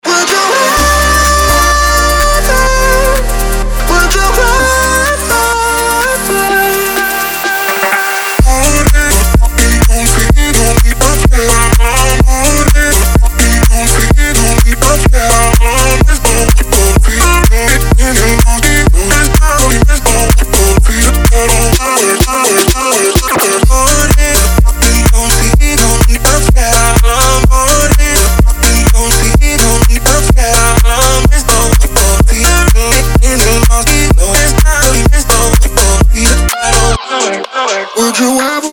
• Качество: 320, Stereo
мужской голос
громкие
dance
EDM
club
house